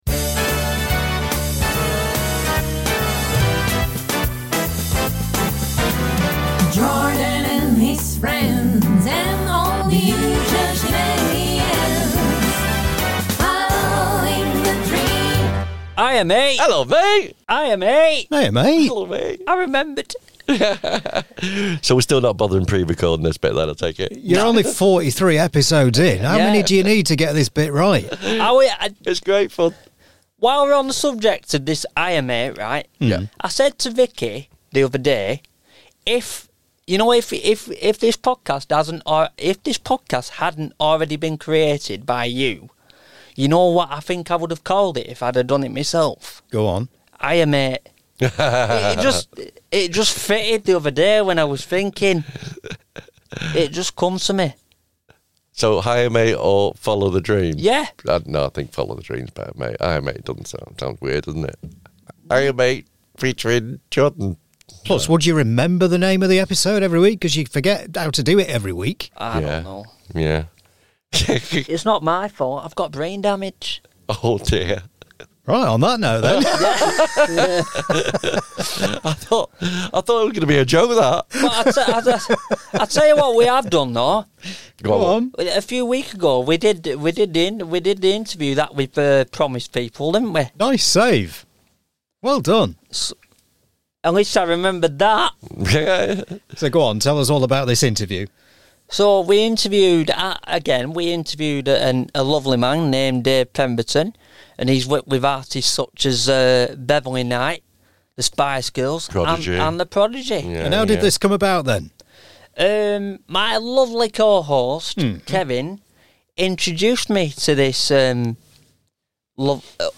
The first BIG INTERVIEW of 2025!